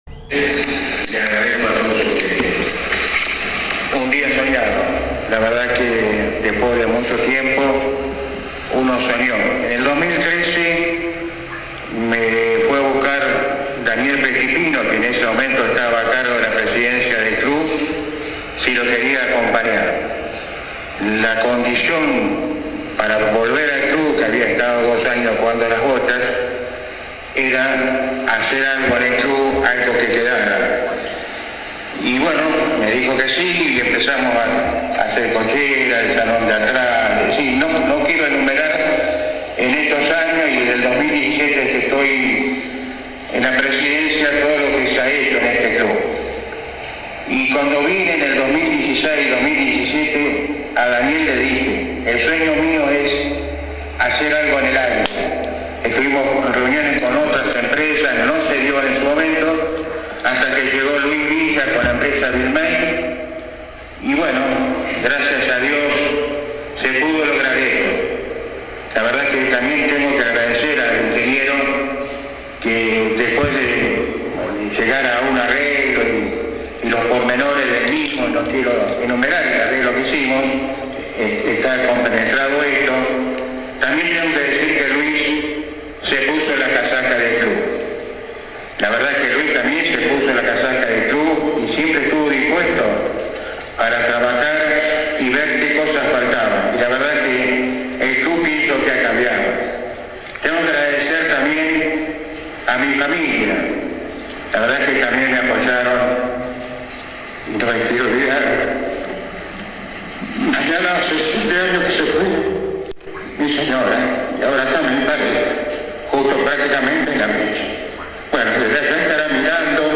Hubo discursos alusivos, recorrida por las nuevas instalaciones, y finalmente un refrigerio de la que participaron todos los presentes.